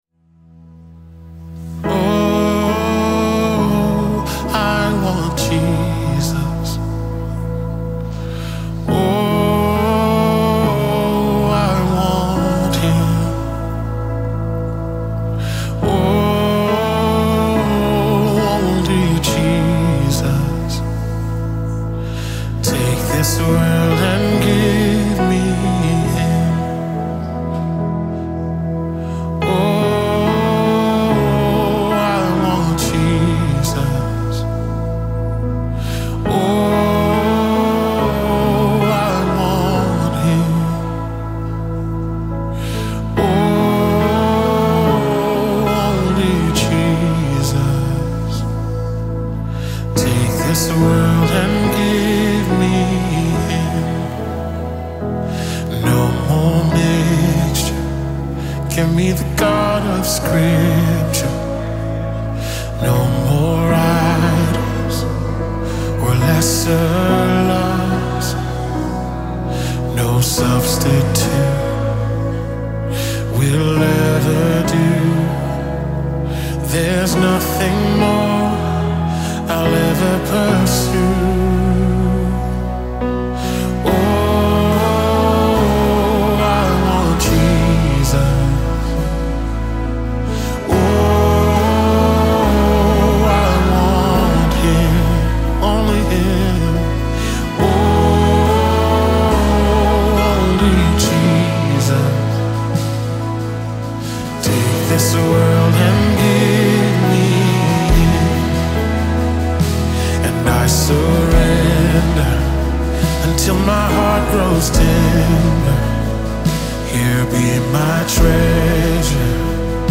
2025 single